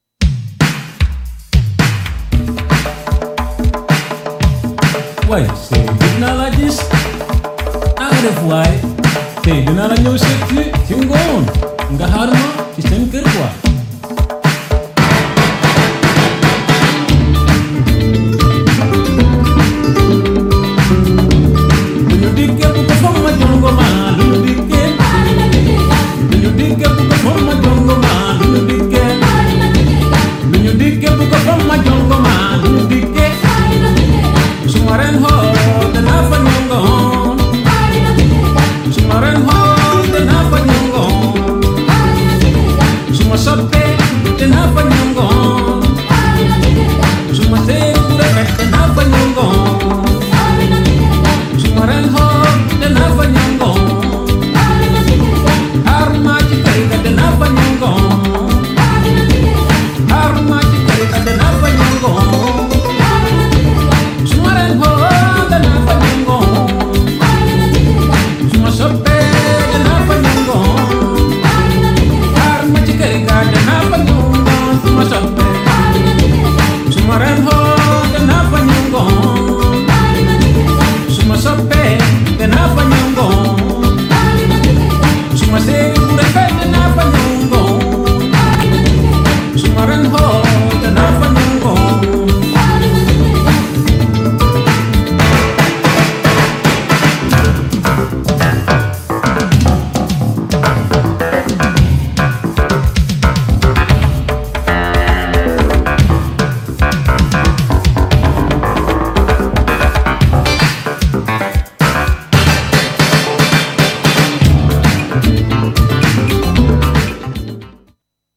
GENRE Dance Classic
BPM 101〜105BPM